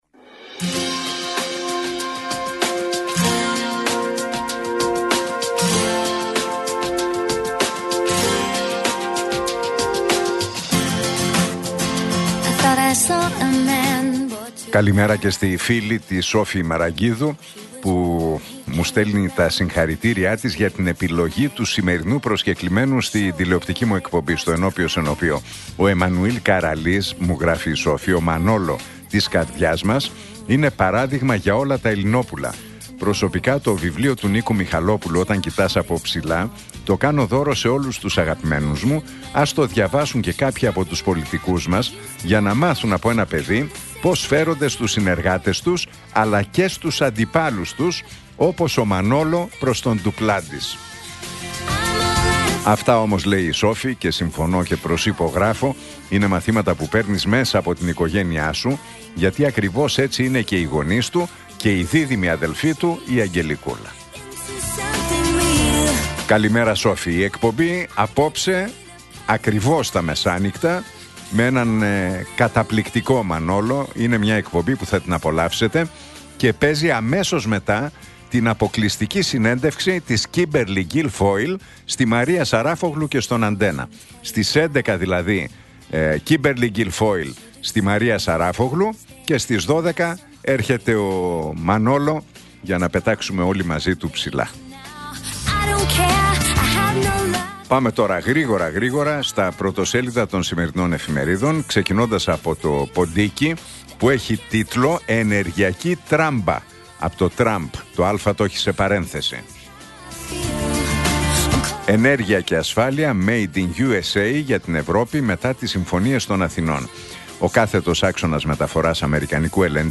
Ακούστε την εκπομπή του Νίκου Χατζηνικολάου στον ραδιοφωνικό σταθμό RealFm 97,8, την Πέμπτη 13 Νοεμβρίου 2025.